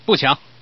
Man_NoRob.mp3